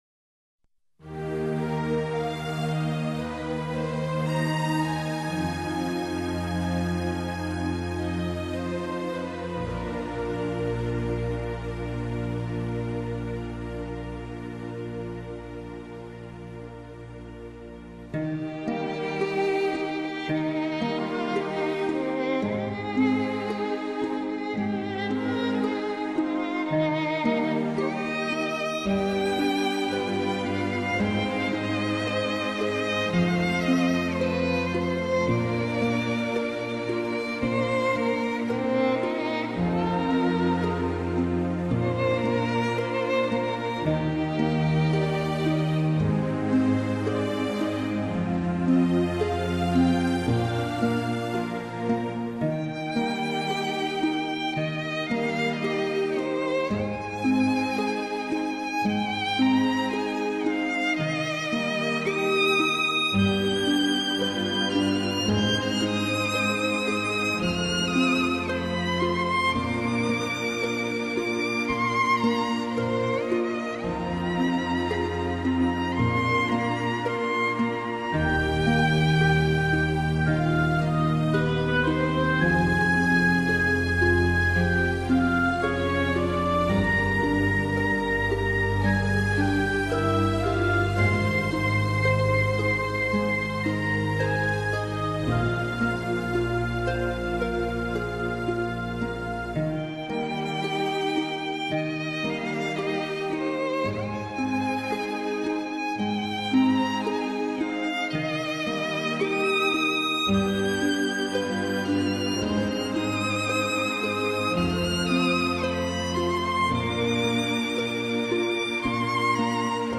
Genero:Instrumental